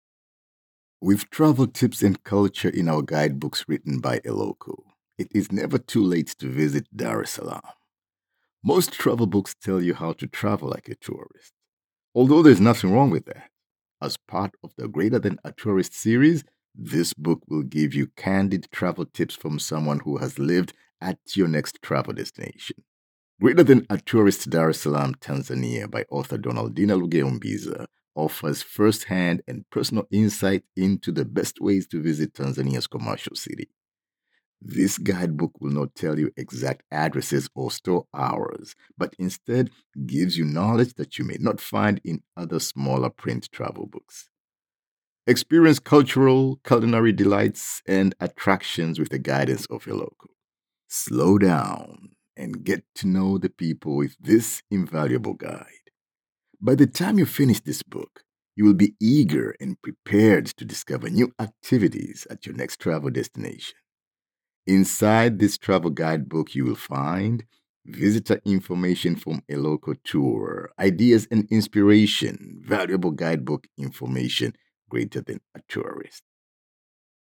Deep and melifluous.
Accents and Dialects
African, Middle East,
Middle Aged